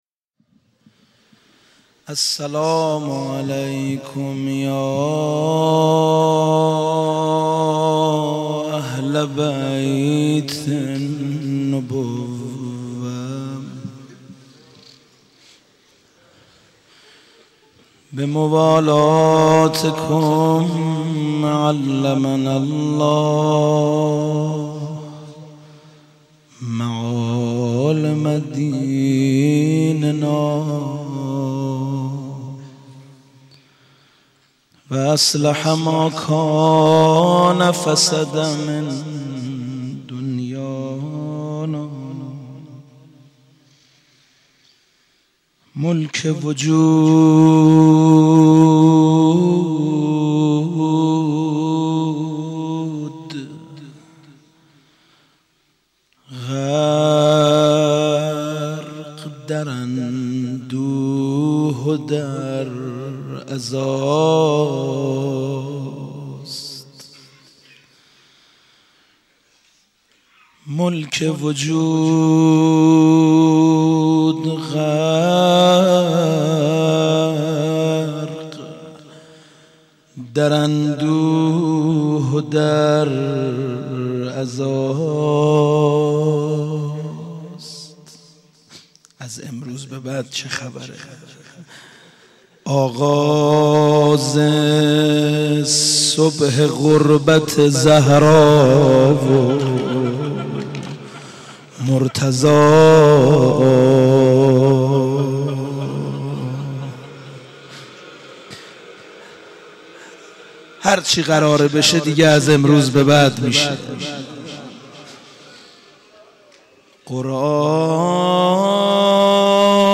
ظهر شهادت امام حسن مجتبی (ع) مسجد امیر
با مداحی حاج سید مهدی میرداماد برگزار شد
بخش اول:روضه بخش دوم:روضه بخش سوم:زمینه بخش چهارم:زمینه لینک کپی شد گزارش خطا پسندها 0 اشتراک گذاری فیسبوک سروش واتس‌اپ لینکدین توییتر تلگرام اشتراک گذاری فیسبوک سروش واتس‌اپ لینکدین توییتر تلگرام